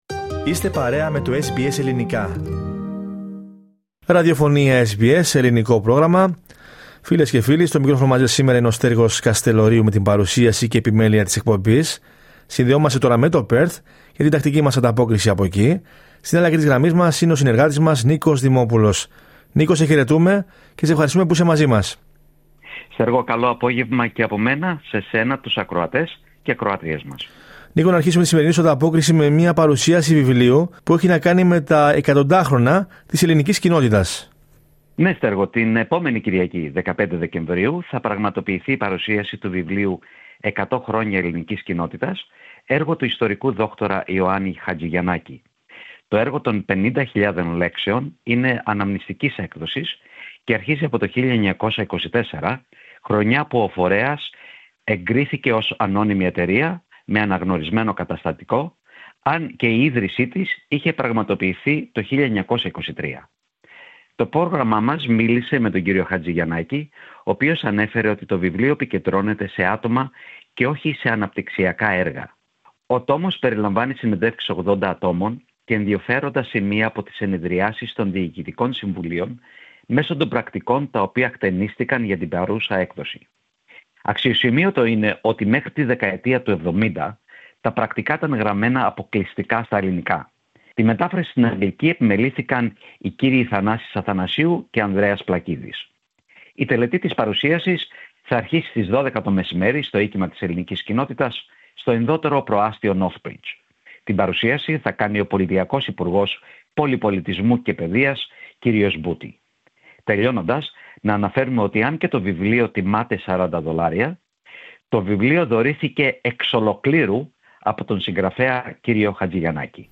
Περισσότερα στην ανταπόκριση